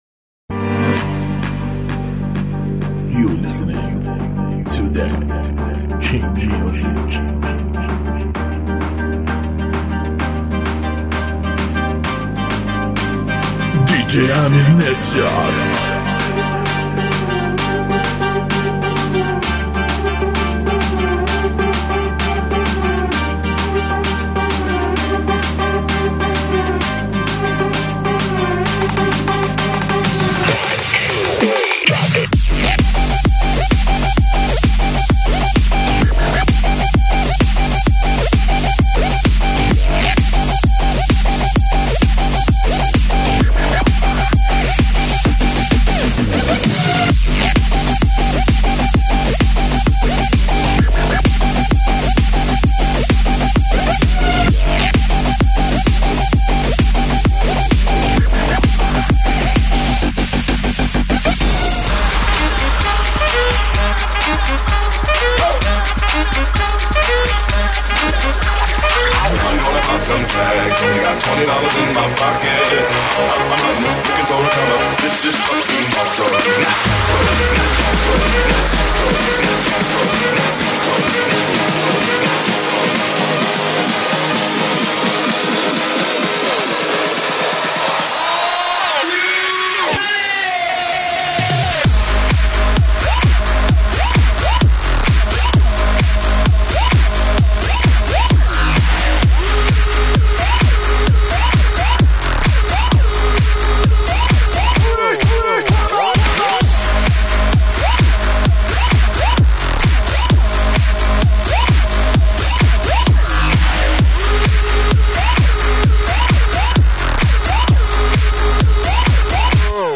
آهنگ شاد
آهنگ شاد عروسی